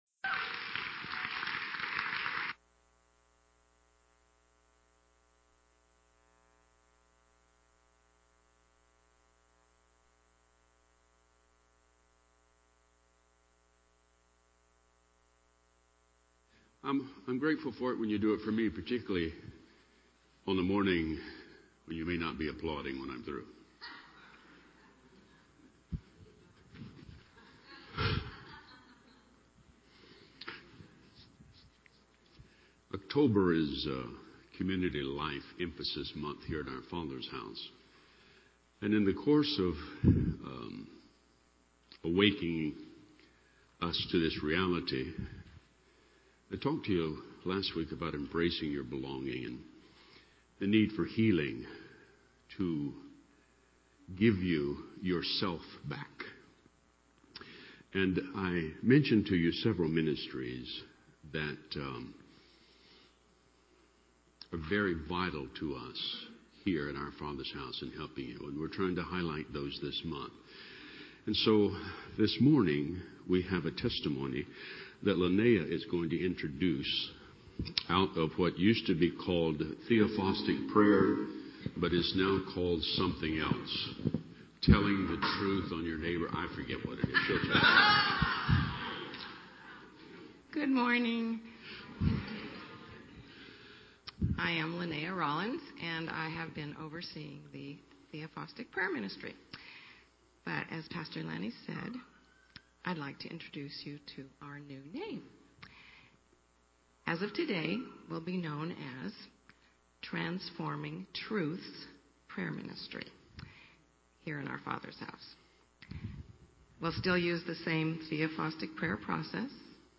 In a message titled "Owning Your Belonging"